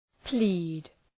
Προφορά
{pli:d}